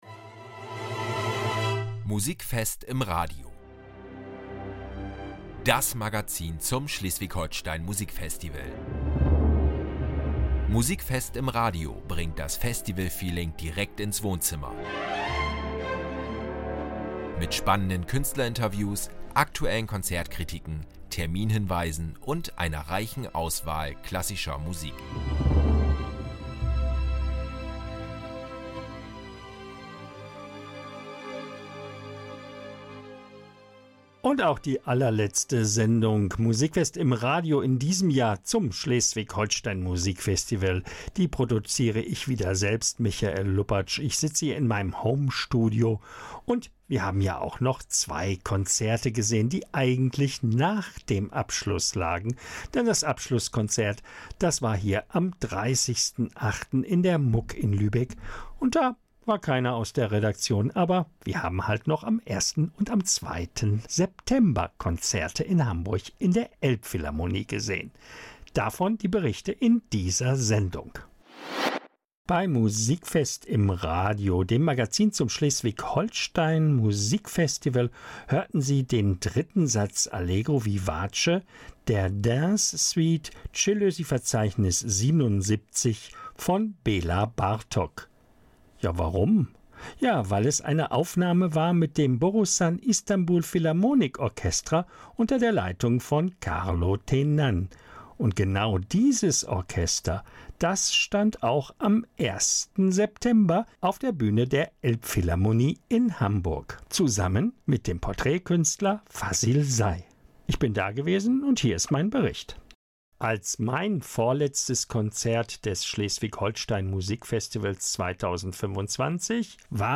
In der finalen Folge von Musikfest im Radio entfaltet das SHMF noch einmal seine ganze Bandbreite: große Orchesterklänge, ein aufstrebender Stern in der Elbphilharmonie – und die Redaktion zieht ihr Fazit zum Festivaljahr.